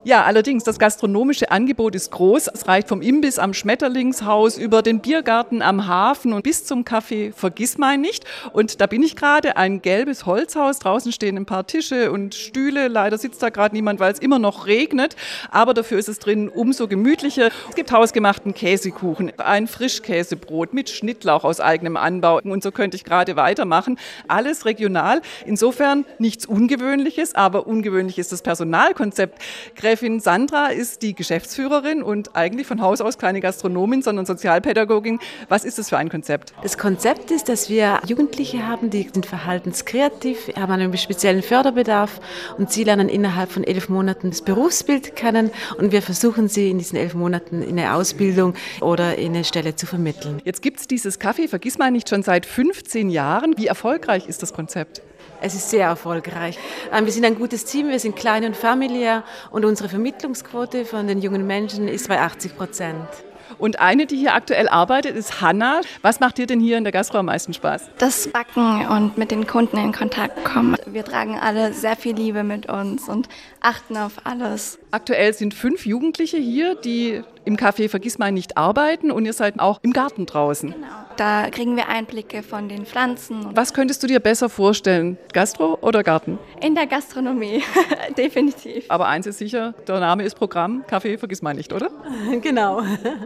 aus dem Café Vergissmeinnicht: